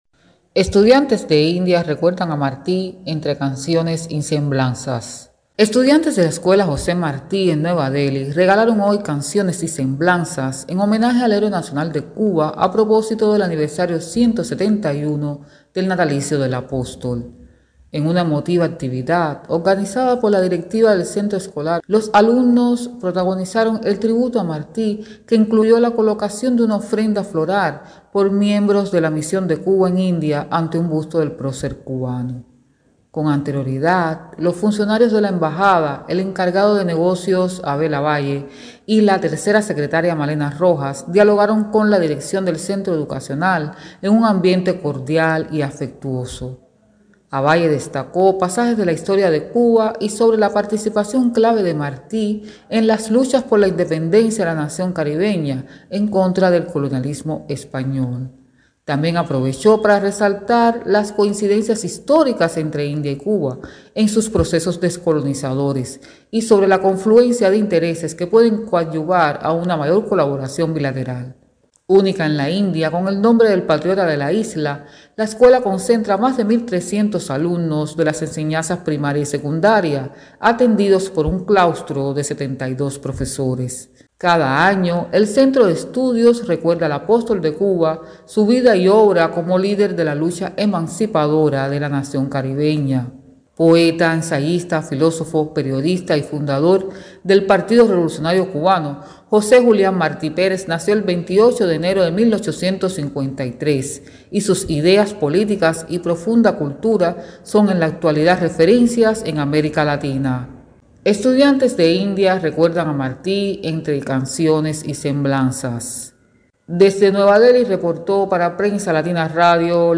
Estudiantes de la escuela José Martí Sarvodaya Vidyalaya regalaron hoy canciones y semblanzas en homenaje al Héroe Nacional de Cuba a propósito del aniversario 171 del natalicio del Apóstol.